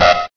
blastDoorAlarm.ogg